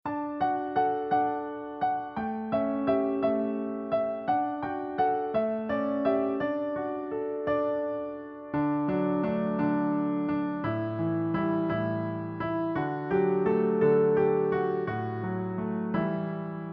Nursery Rhyme Lyrics